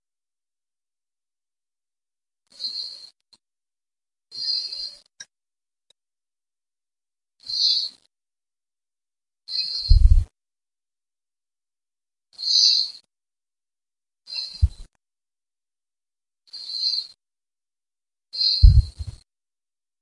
随机 " 随机的尖叫声
描述：这是试图记录我的呼吸声后，从我的笔记本麦克风中发出的声音，EPIC FAIL